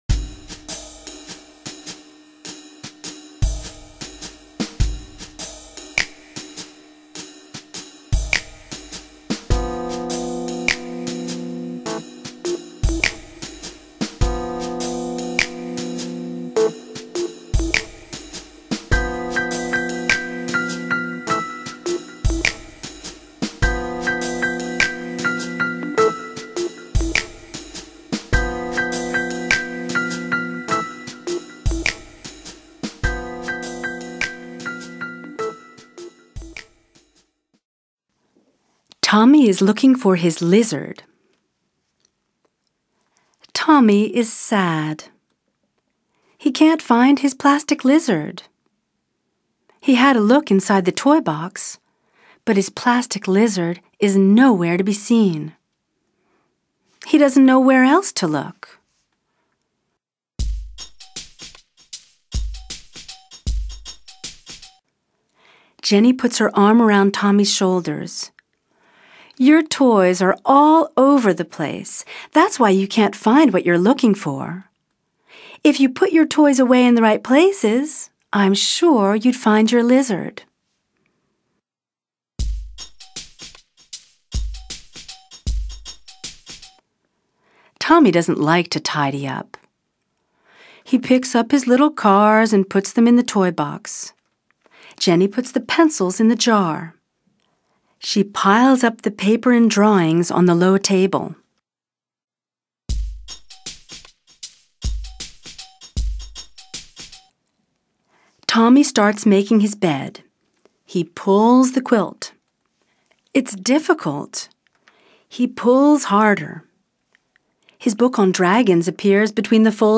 Extrait en anglais